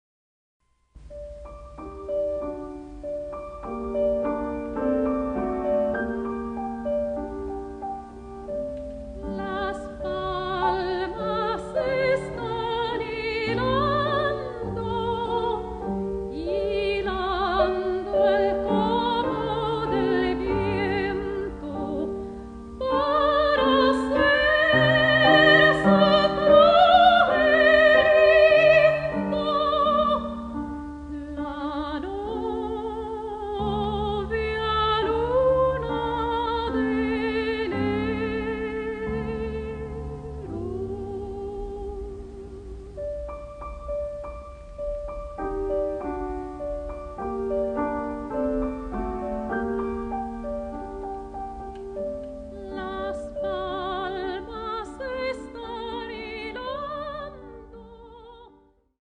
Muchas grabaciones que aquí se ofrecen se registraron en presentaciones en vivo durante las décadas de 1950, 1960 y 1970.
CANTO Y PIANO